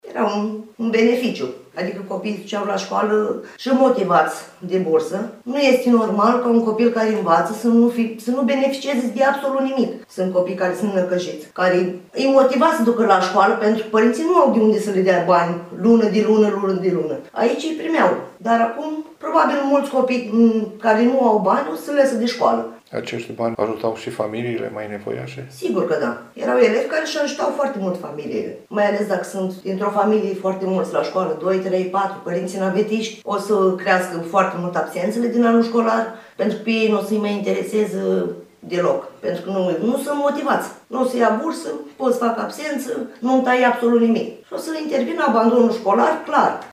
4-iulie-ora-15-Voce-parinte.mp3